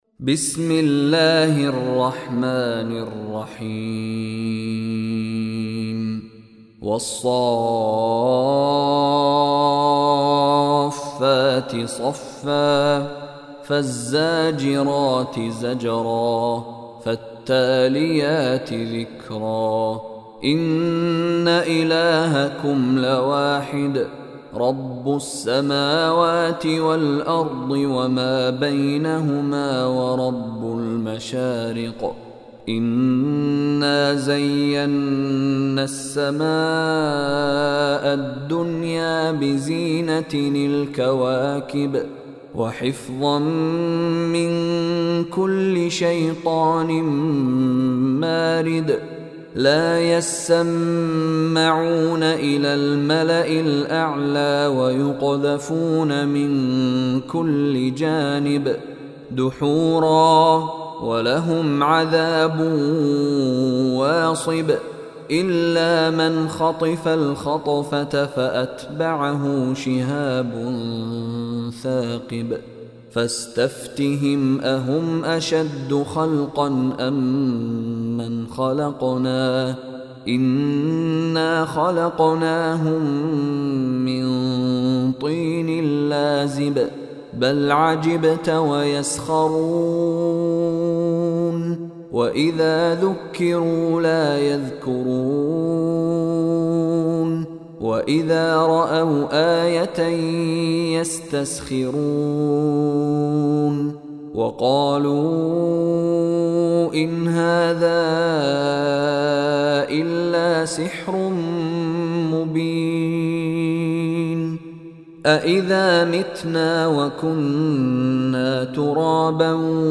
Surah As-Saaffat Recitation by Mishary Rashid
Listen online and download mp3 audio recitation / tilawat of Surah Saffat in the voice of Sheikh Mishary Rashid Alafasy.